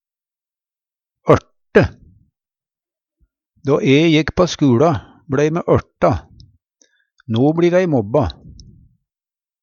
DIALEKTORD PÅ NORMERT NORSK ørte erte Infinitiv Presens Preteritum Perfektum ørte ørta ørta ørta Eksempel på bruk Då e jekk på skuLa, blei me ørta.